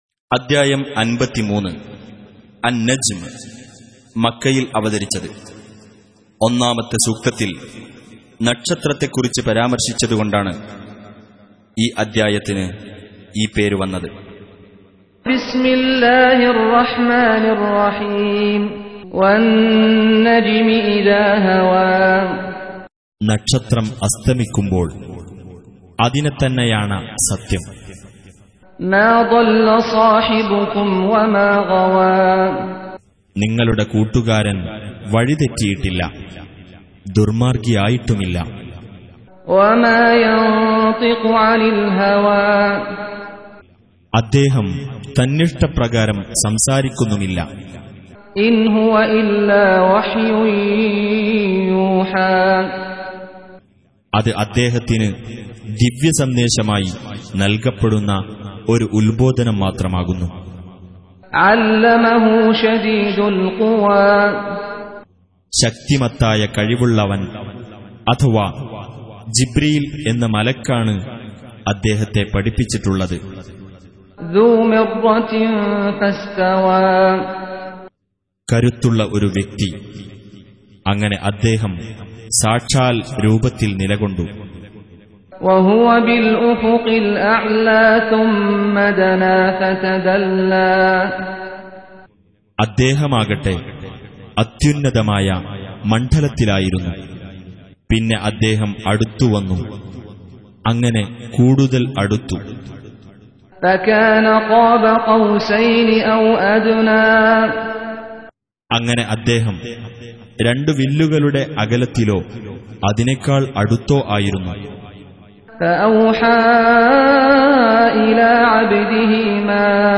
Audio Quran Tarjuman Translation Recitation
Surah Repeating تكرار السورة Download Surah حمّل السورة Reciting Mutarjamah Translation Audio for 53. Surah An-Najm سورة النجم N.B *Surah Includes Al-Basmalah Reciters Sequents تتابع التلاوات Reciters Repeats تكرار التلاوات